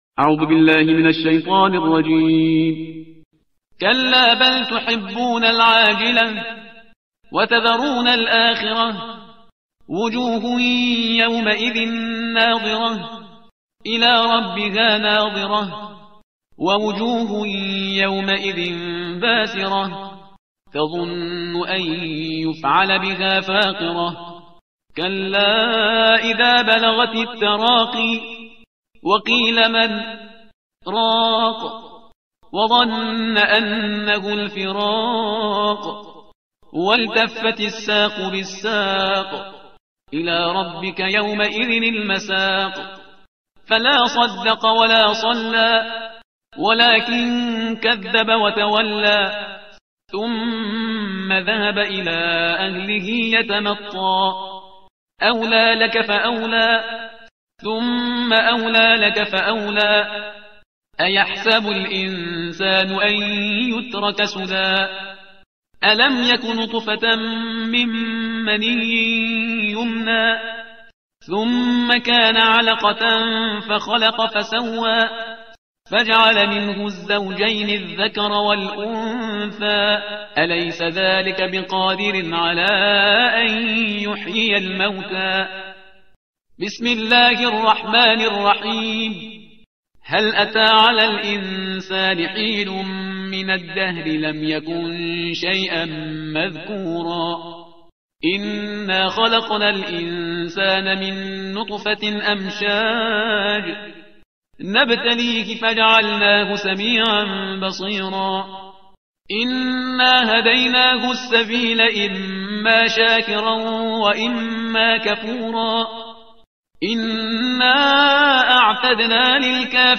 ترتیل صفحه 578 قرآن با صدای شهریار پرهیزگار